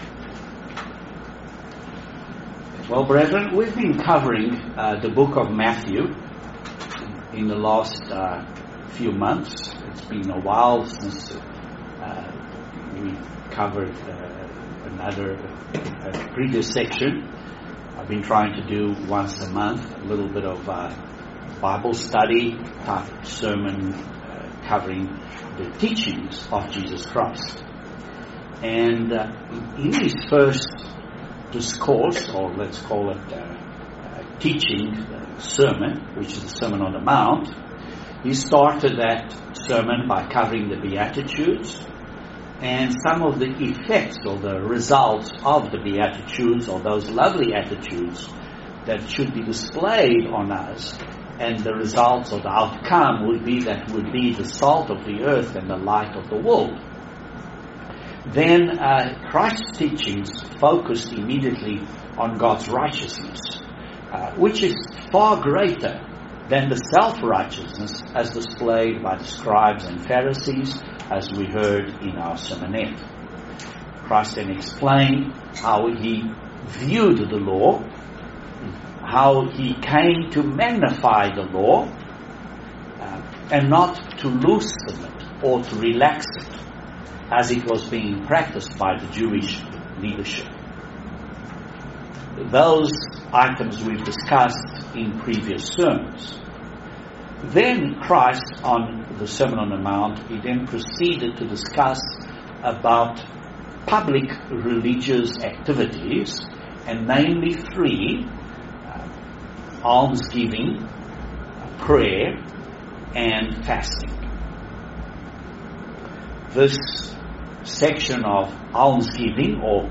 Continuing sermon series on the book of Matthew - chapter 6. Are we doing the right thing before God and others around us ?